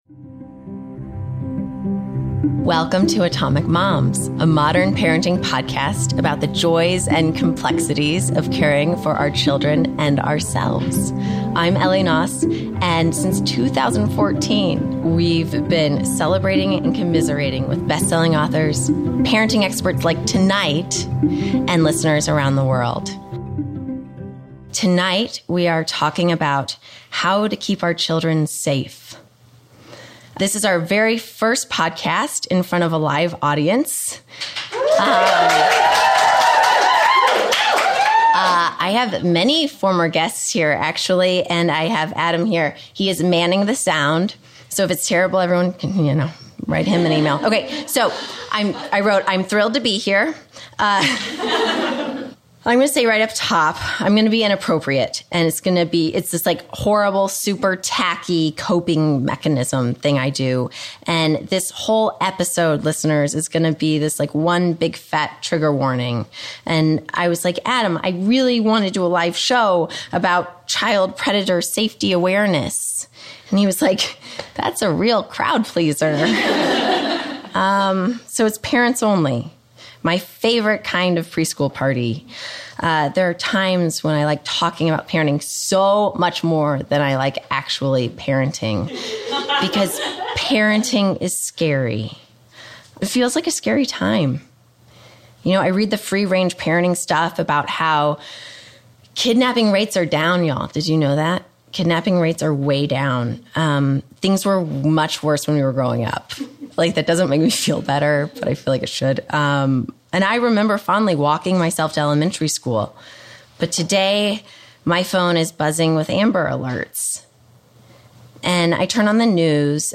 How to Protect Our Children from Sexual Abuse (Live in L.A.)